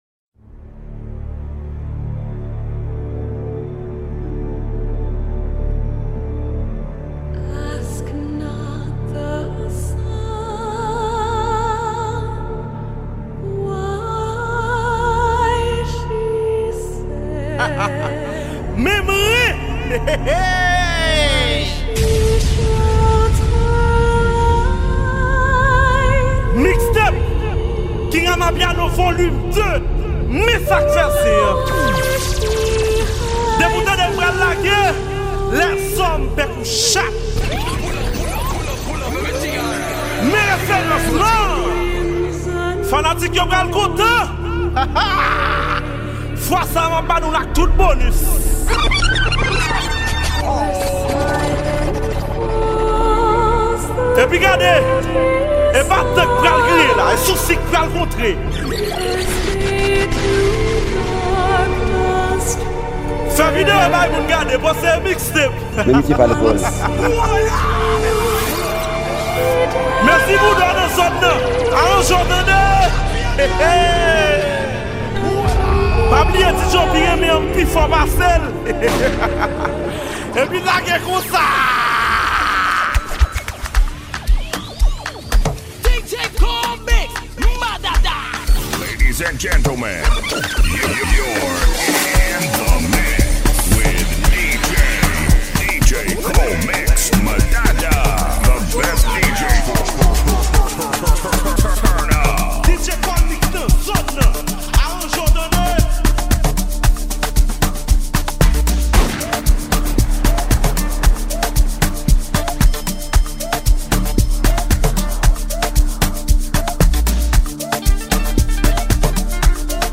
an amazing Disc Jockey
mixtape